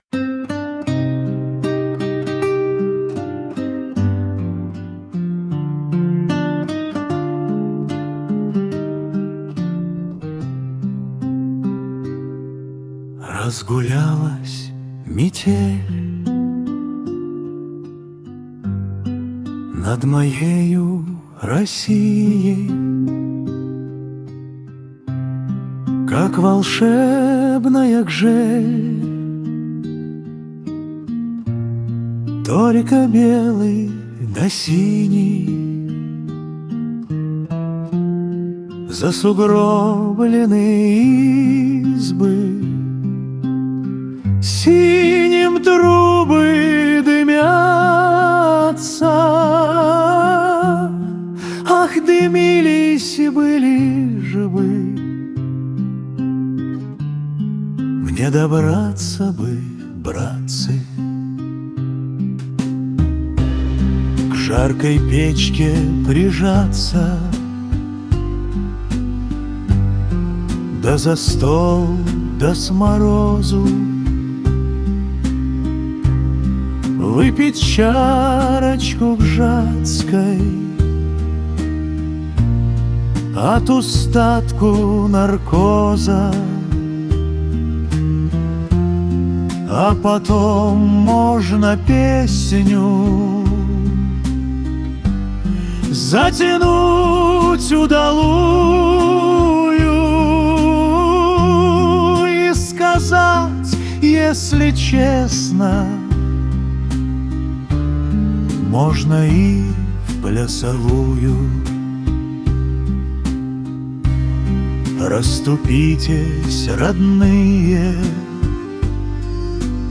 • Аранжировка: Ai
• Жанр: Романс